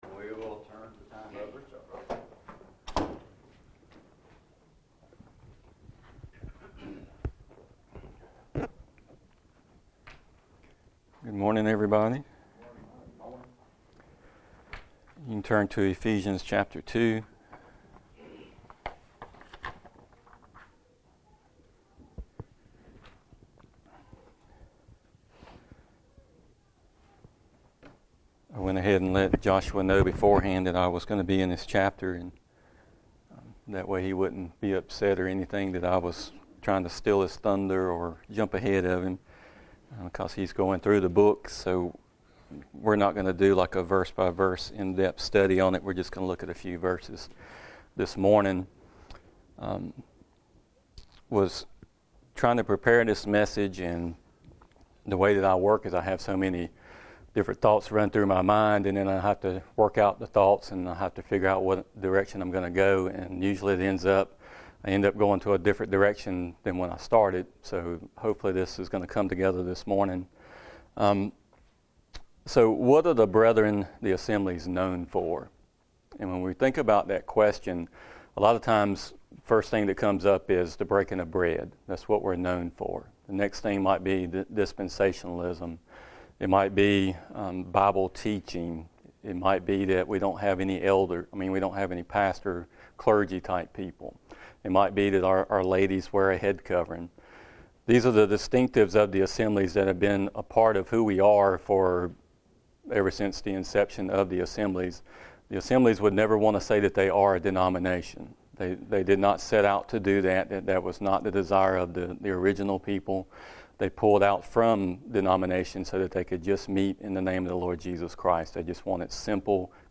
2026 Who we are in Christ Jesus Preacher